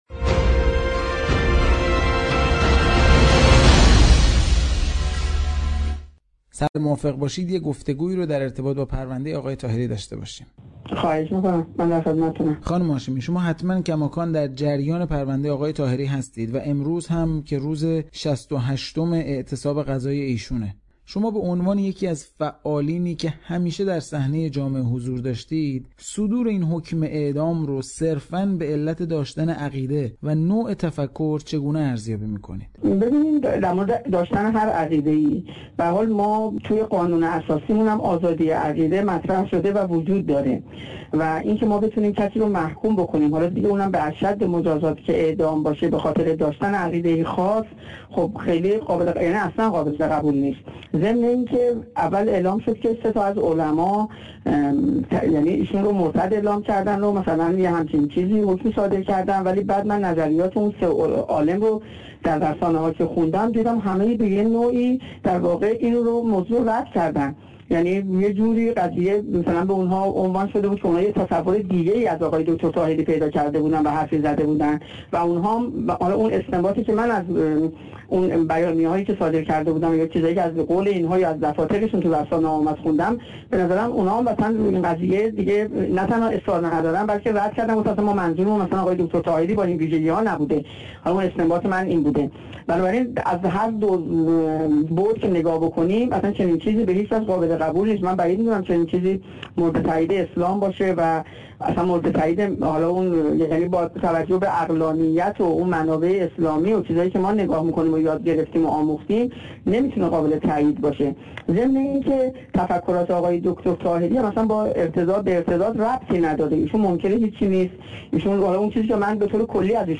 به گزارش مشرق، فائزه هاشمی رفسنجانی در مصاحبه با یک تلویزیون اینترنتی خارج از کشور حکم محمدعلی طاهری، سرکرده فرقه انحرافی عرفان حلقه را زیر سوال برد.